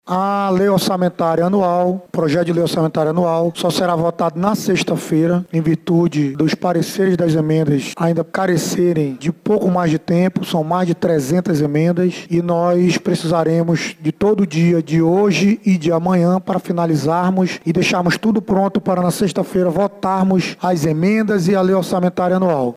A informação é do presidente da Casa, Caio André (PSC).
ADIA-1312-A-CAIO-ANDRE.mp3